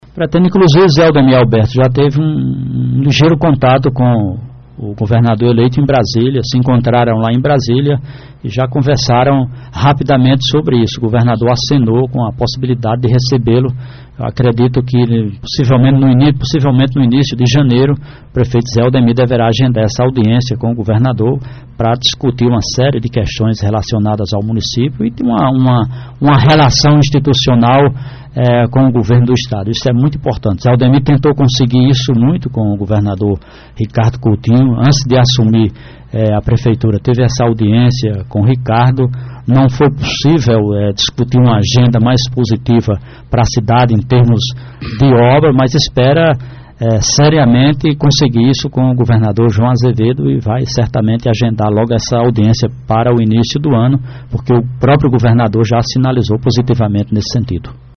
Em entrevista no programa Rádio Vivo da Alto Piranhas desta terça – feira(04), o secretário de Governo e Articulação da gestão municipal de Cajazeiras, Jornalista José Anchieta, confirmou que o prefeito de Cajazeiras, José Aldemir(PP), terá audiência com o governador eleito, João Azevêdo (PSB), a partir do mês de janeiro de 2019.